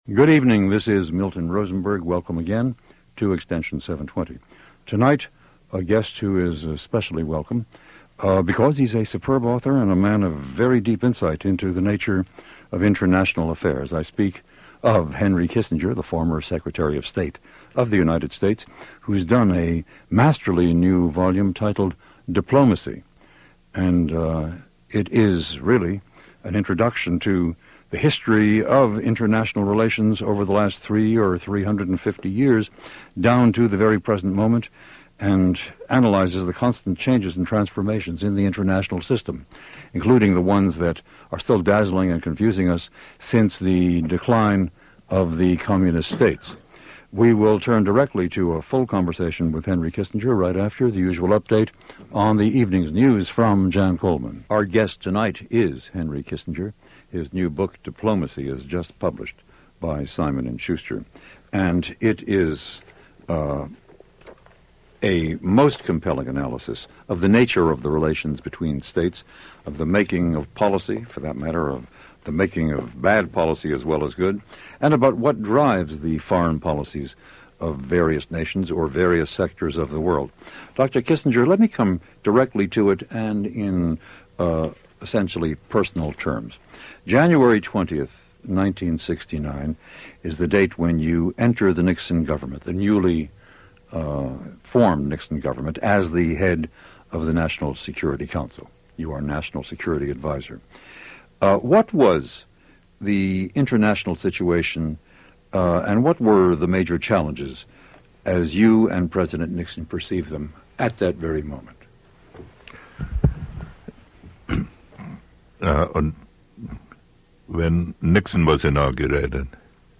An Extended Conversation With Henry Kissinger